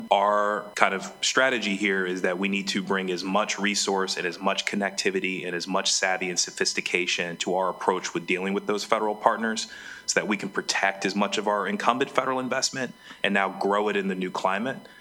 Governor Wes Moore’s Chief of Staff Fagan Harris testified Monday in the House Public Safety and Administrative Committee on cuts the Department of Legislative Services is recommending in regards to the state’s federal governmental affairs contract.